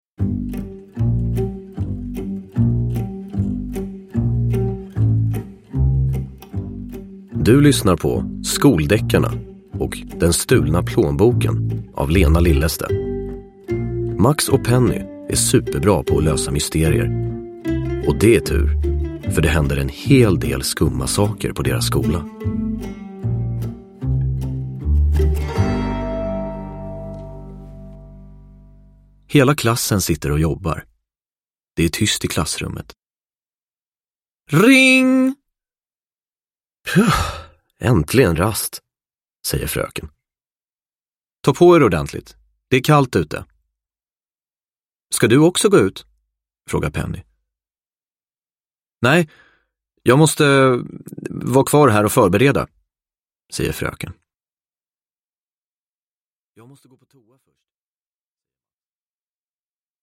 Den stulna plånboken – Ljudbok